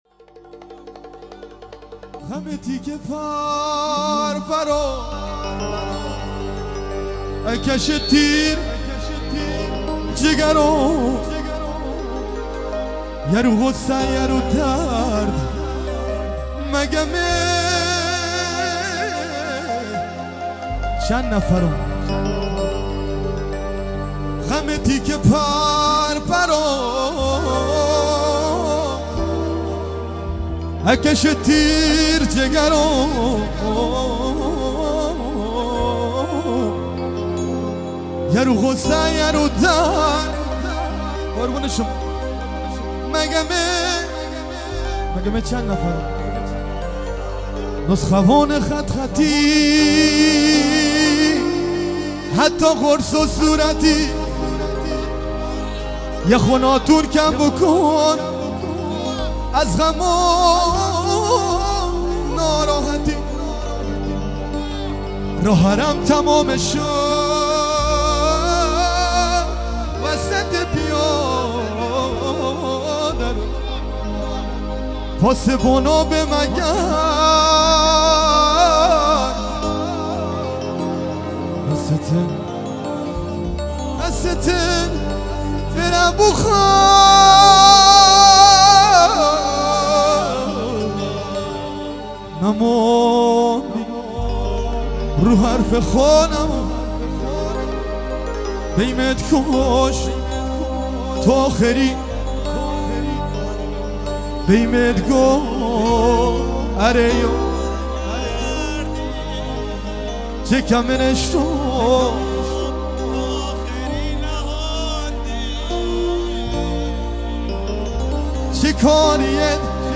مجموعه ترانه های بندری . جزیرتی و سه پا کیبورد
درامز
عود